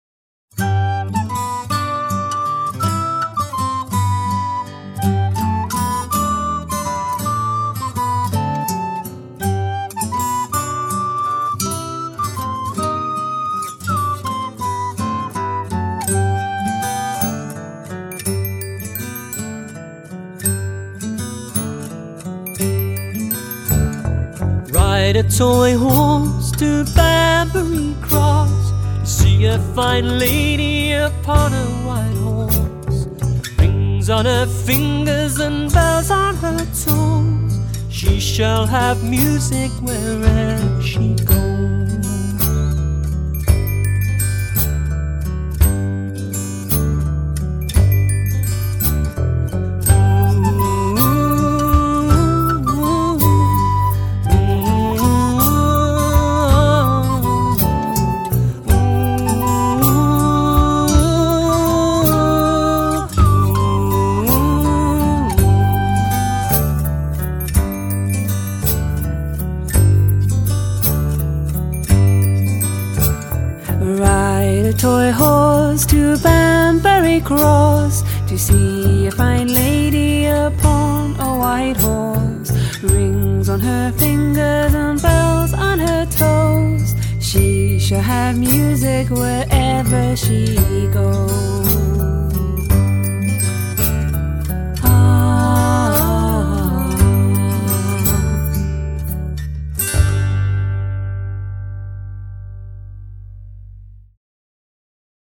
TraditionalFolk